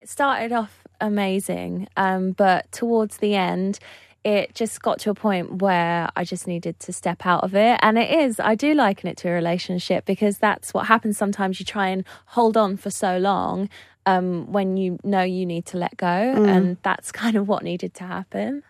Speaking to us Leona said the split from Simon Cowell felt a bit like a break up..